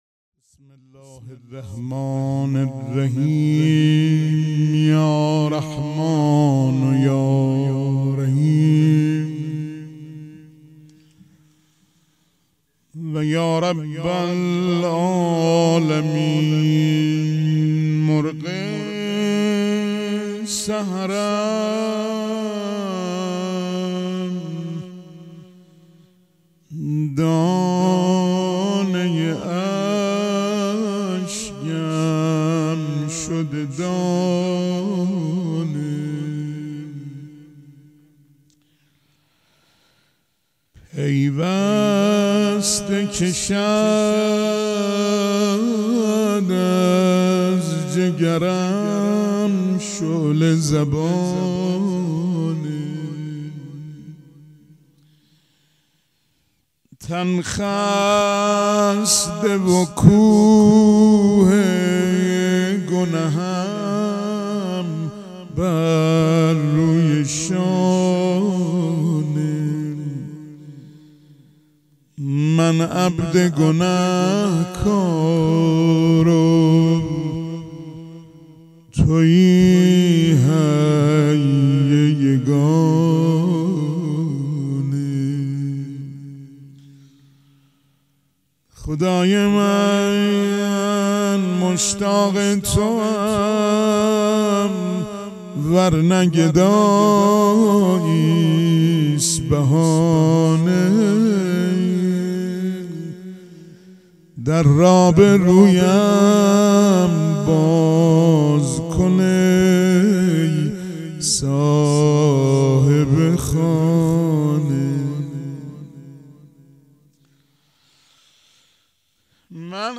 پیش منبر
مراسم مناجات شب بیست و دوم ماه رمضان سه شنبه ۱۴ اردیبهشت ۱۴۰۰ حسینیه ریحانة‌الحسین(س)